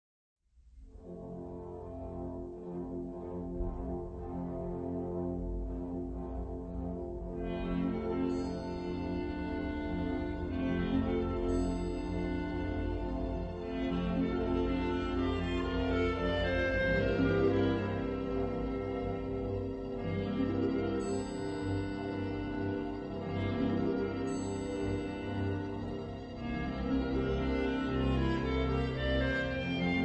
skladanka najpiekniejszych walcow